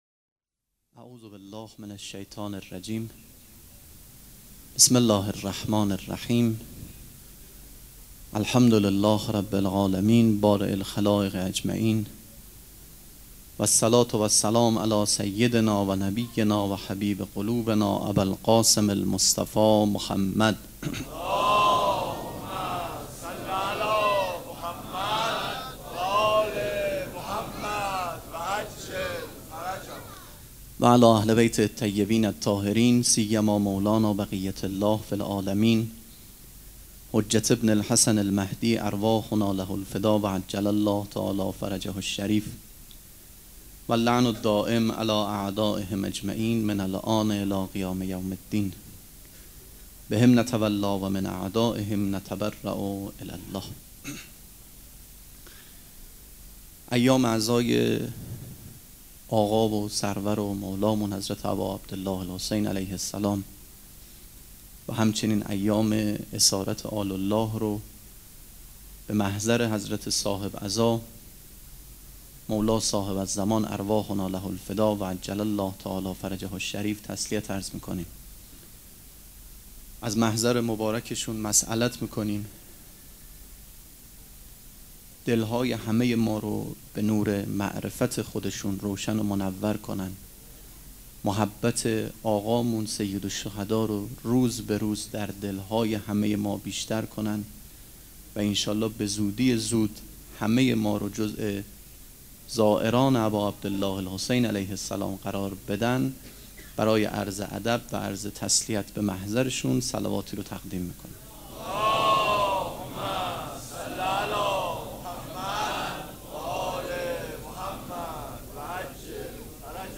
سخنرانی
مراسم عزاداری شب چهارم